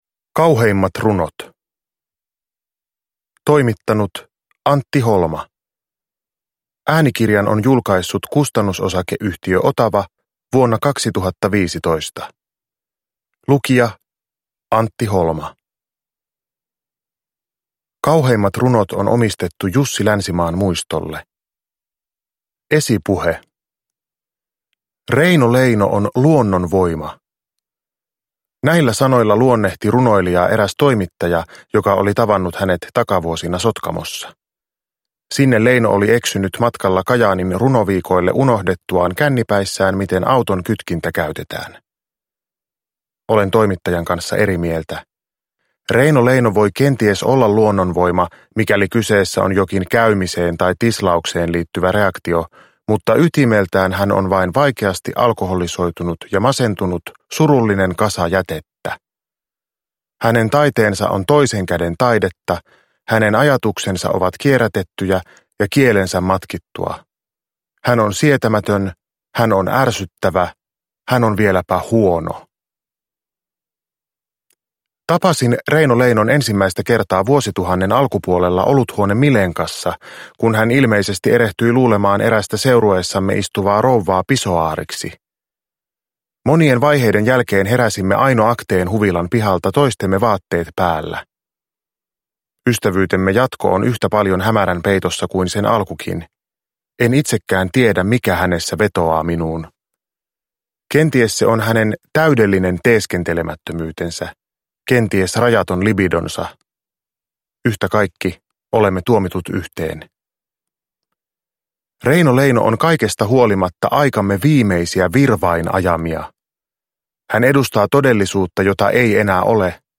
Kauheimmat runot – Ljudbok – Laddas ner
Uppläsare: Antti Holma